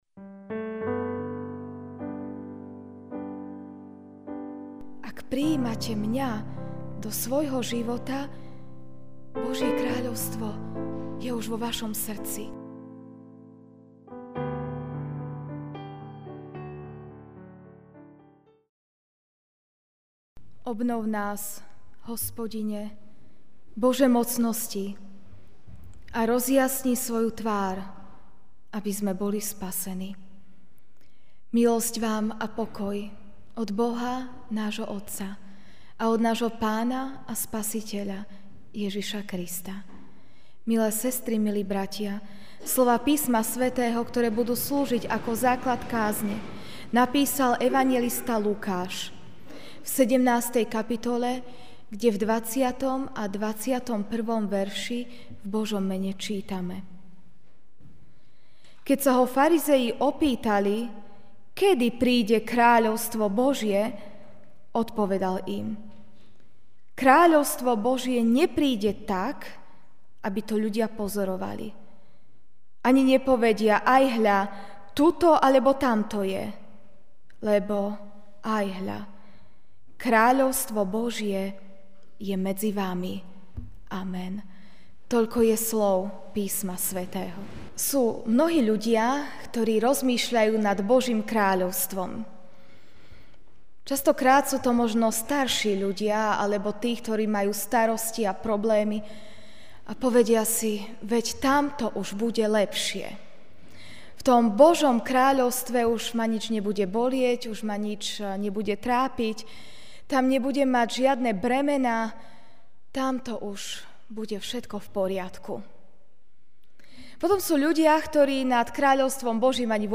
Kráľovstvo Božie prichádza - Evanjelický a.v. cirkevný zbor v Žiline
Ranná kázeň